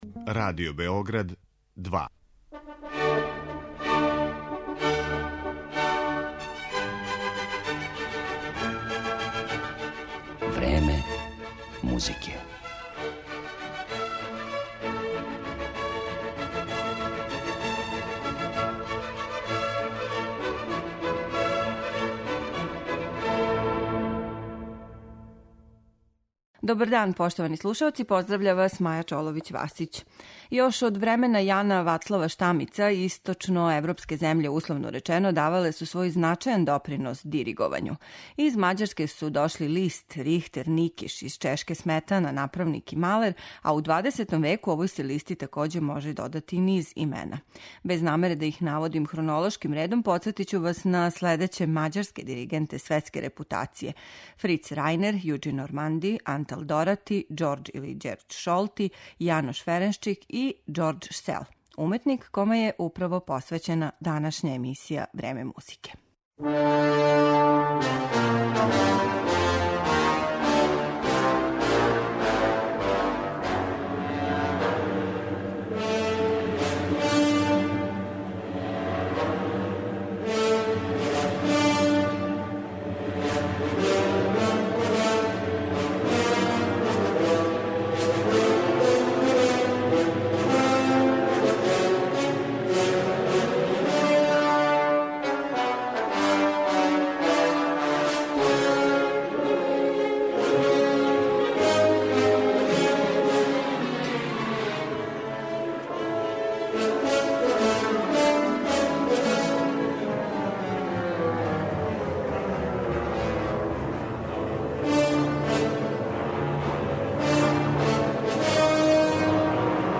Емисија класичне музике